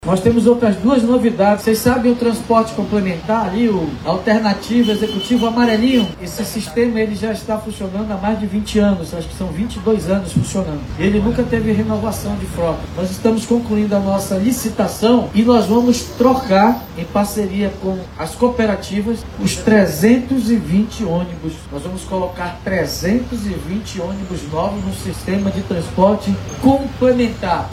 A cerimônia ocorreu na Avenida das Torres, no sentido Cidade Nova/Coroado, com o objetivo de melhorar a mobilidade urbana.